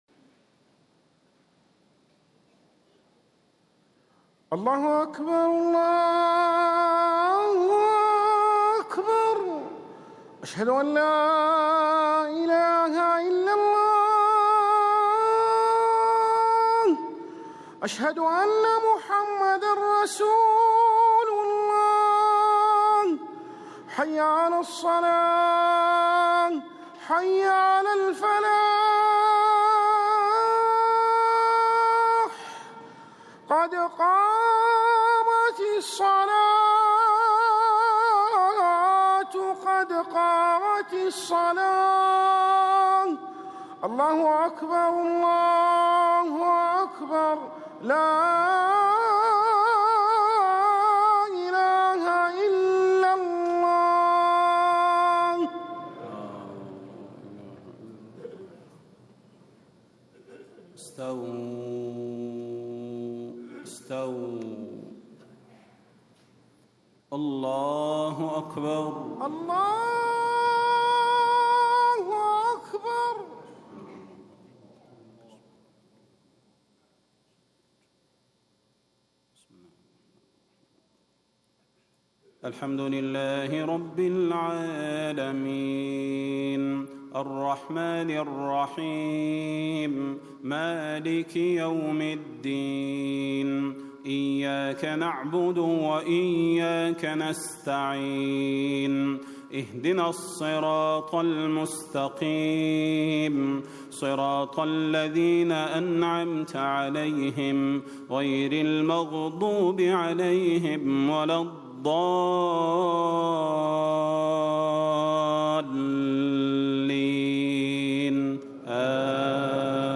صلاة الجمعة 13 شعبان 1437هـ سورتي الفلق و الناس > 1437 🕌 > الفروض - تلاوات الحرمين